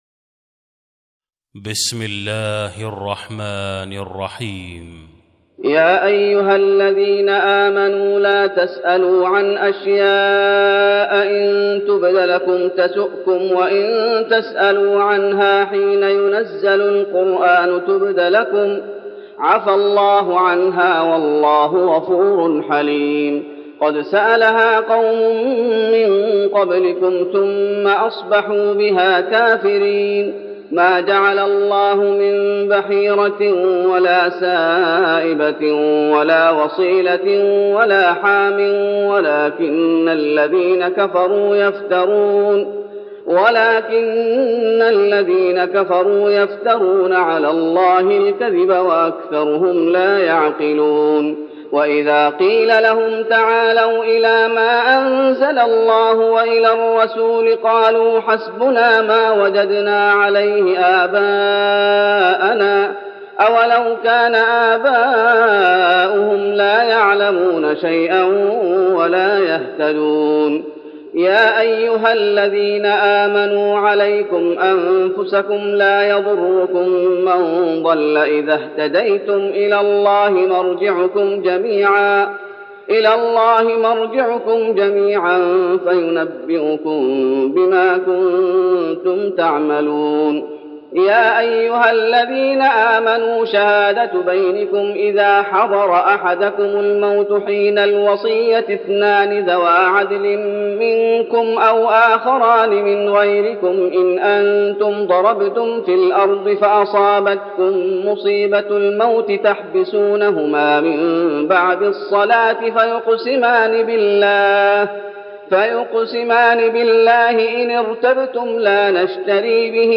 تراويح رمضان 1412هـ من سورة المائدة (101-120) Taraweeh Ramadan 1412H from Surah AlMa'idah > تراويح الشيخ محمد أيوب بالنبوي 1412 🕌 > التراويح - تلاوات الحرمين